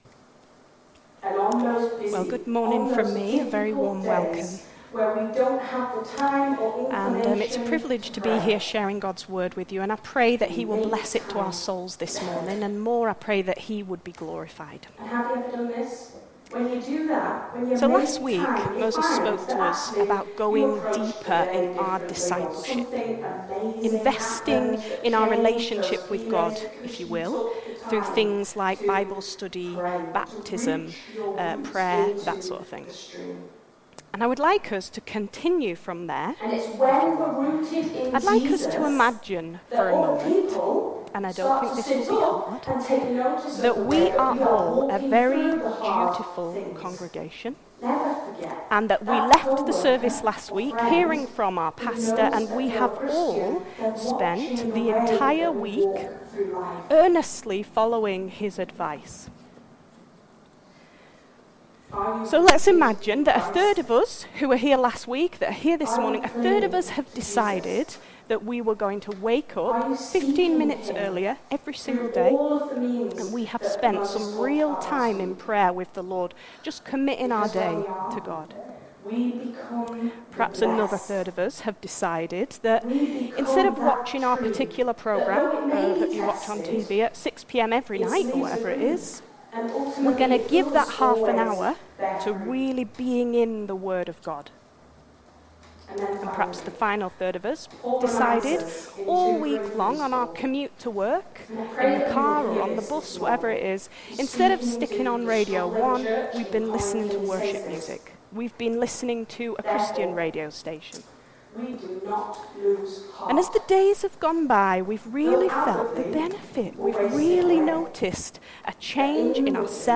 Passage: Jeremiah 17:5-10, Luke 6:17-26 Service Type: Sunday Morning
02-16-sermon.mp3